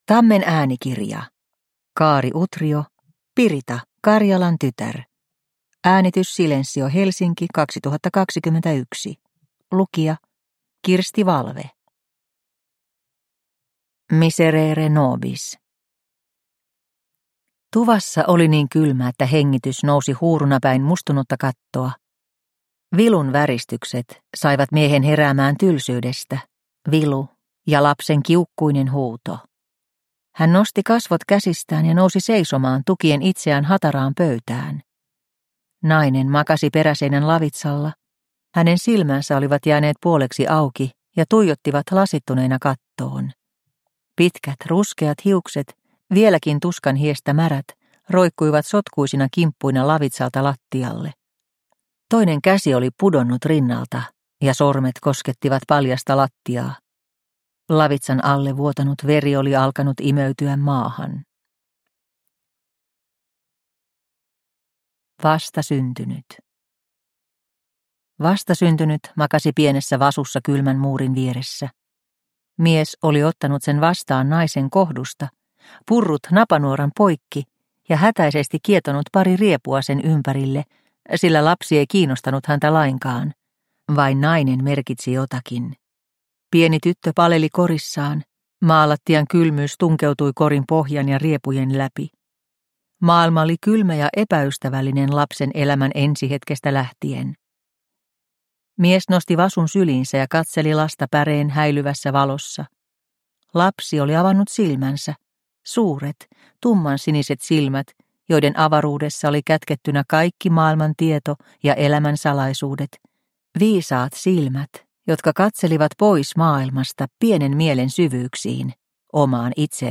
Pirita – Ljudbok – Laddas ner
Produkttyp: Digitala böcker